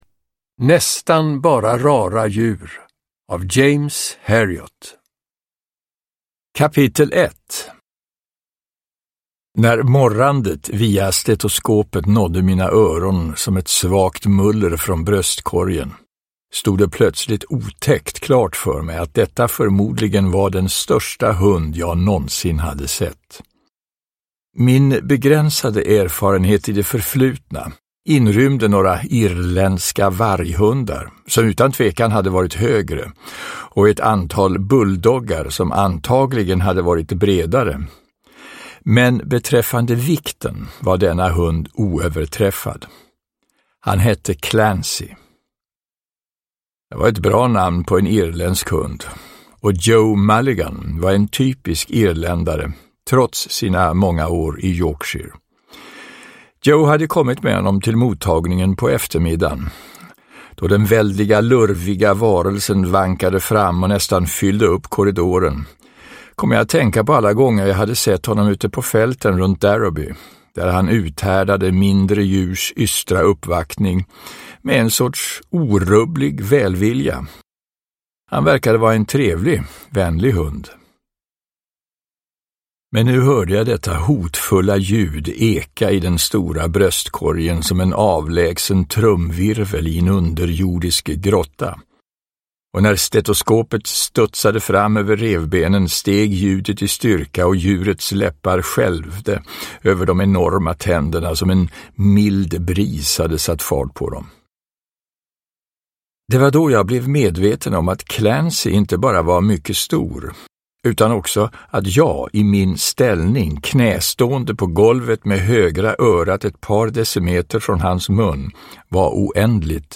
Uppläsare: Björn Granath